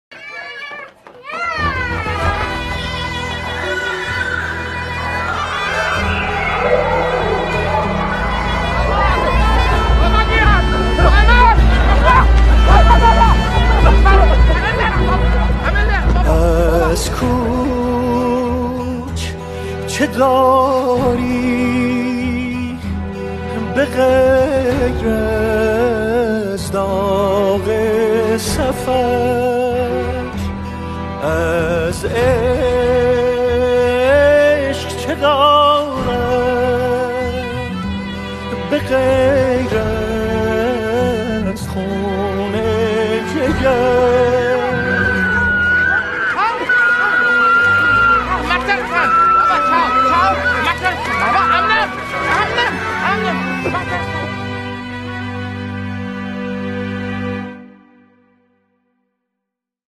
دانلود آهنگ تیتراژ فیلم
تیتراژ پایانی فیلم